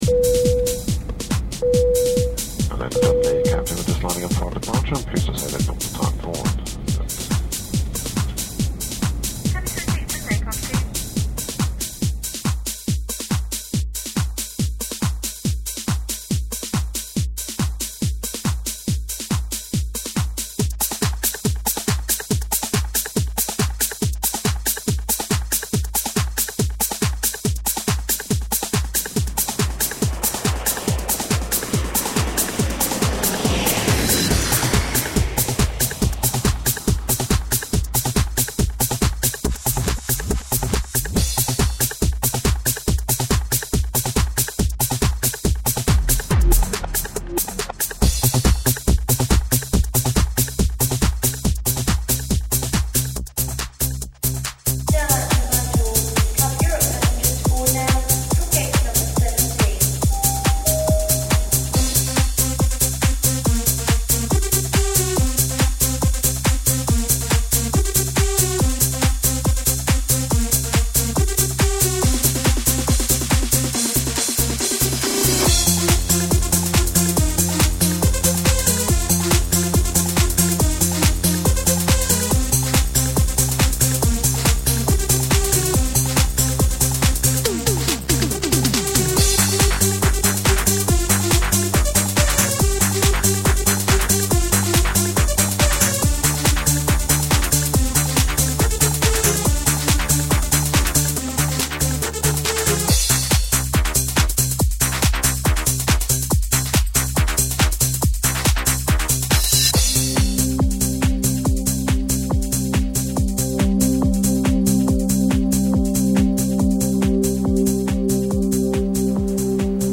4,2 MB 2000 Trance